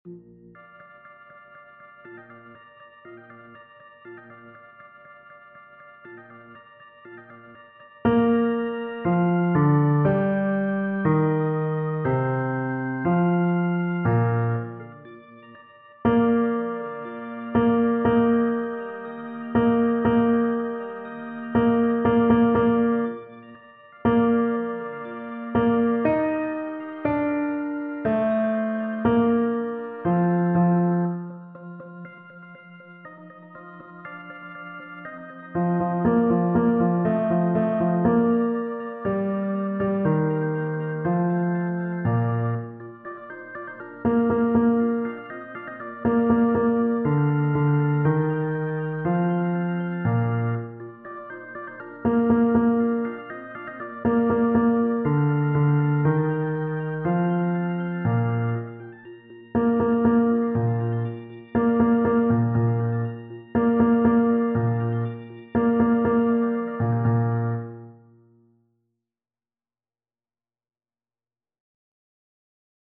Fichiers de Travail des Basses